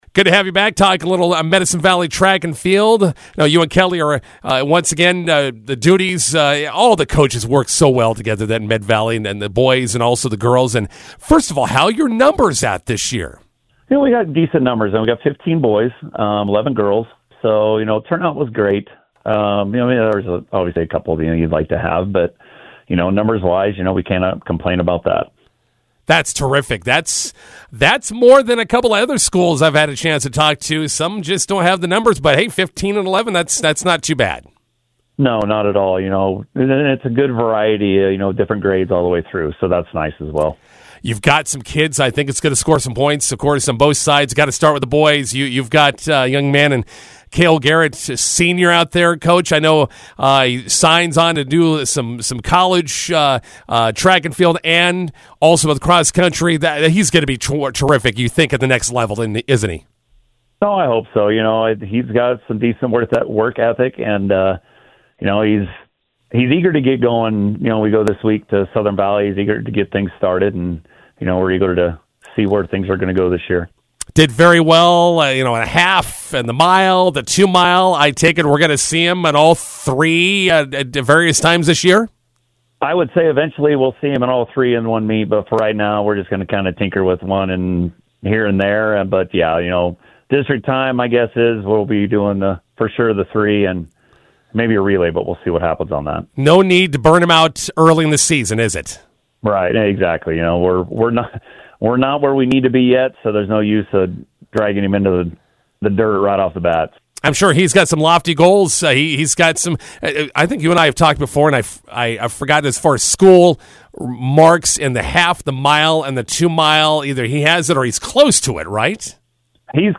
INTERVIEW: Medicine Valley Track and Field heads to Southern Valley for season opening meet on Friday.